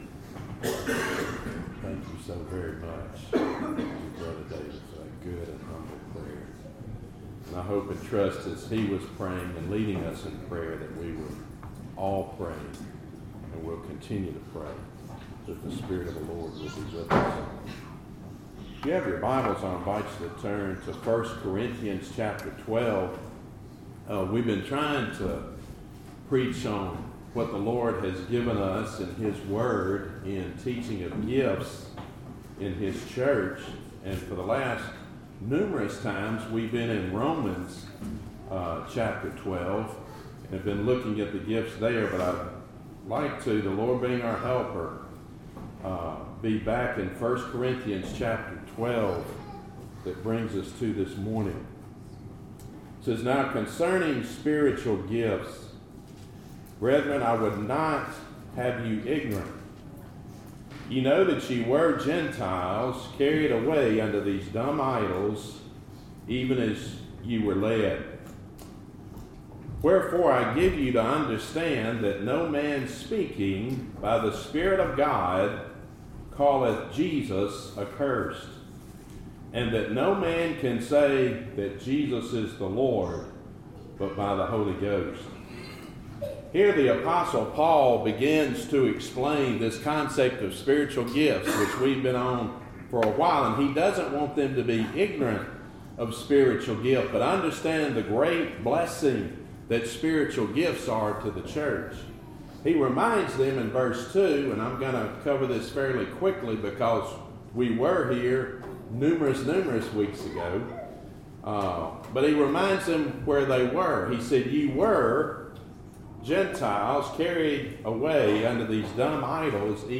Series: Gifts in the Church Topic: Sermons